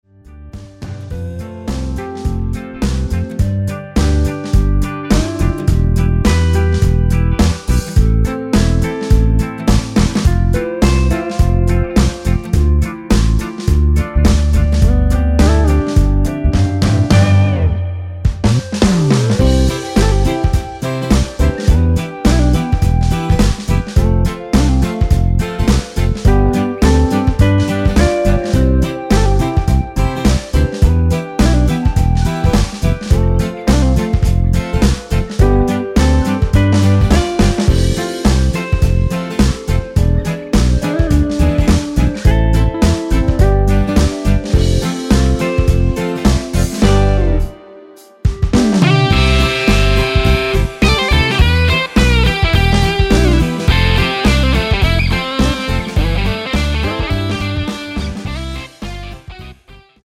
원키 멜로디 포함된 MR 입니다.
노래방에서 노래를 부르실때 노래 부분에 가이드 멜로디가 따라 나와서
앞부분30초, 뒷부분30초씩 편집해서 올려 드리고 있습니다.
중간에 음이 끈어지고 다시 나오는 이유는